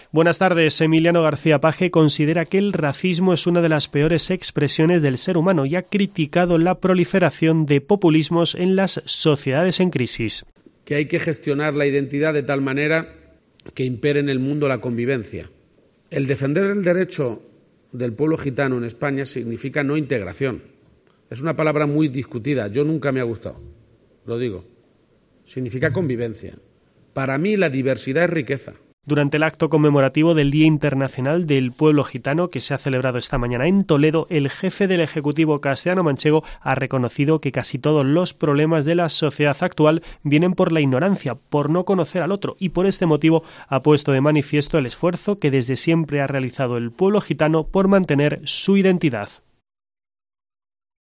Durante el acto conmemorativo del Día Internacional del Pueblo Gitano celebrado hoy en Toledo